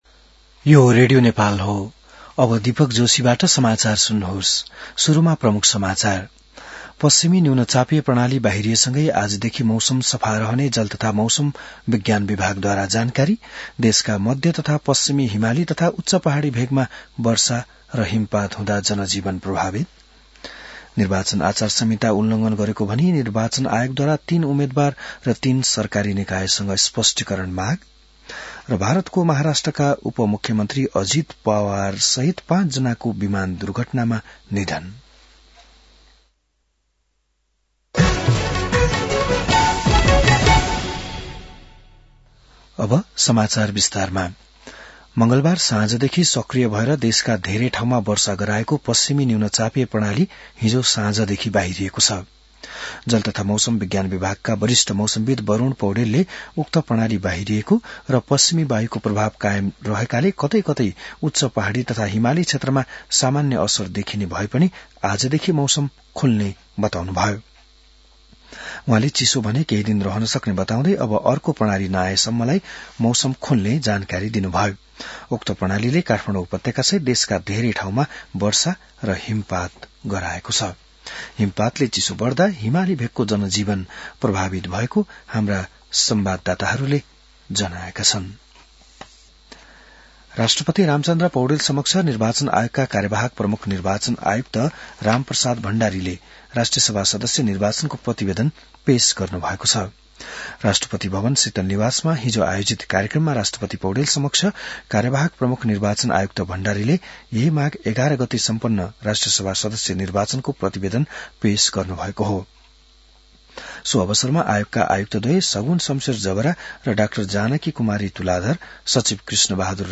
बिहान ९ बजेको नेपाली समाचार : १५ माघ , २०८२